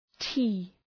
Προφορά
{ti:} (Ουσιαστικό) ● τσάι